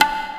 soft-hitnormal2.ogg